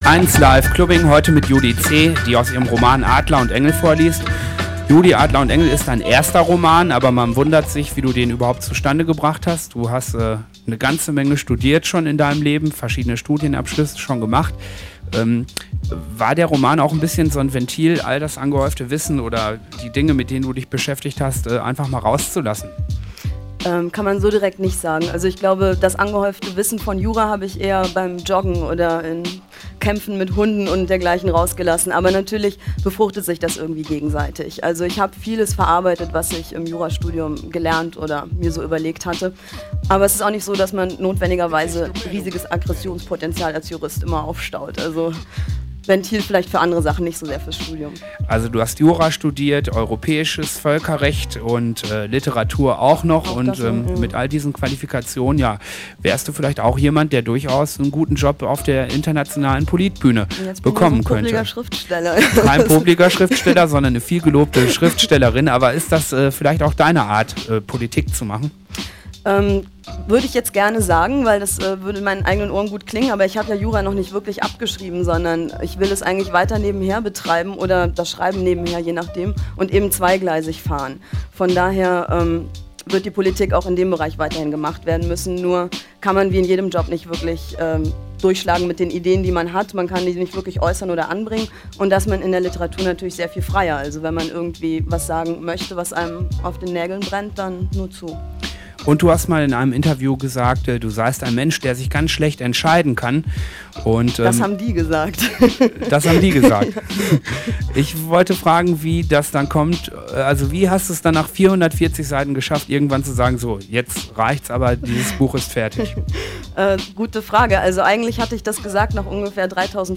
Eins Live "Klubbing" vom 05.10.01. Mit freundlicher Genehmigung.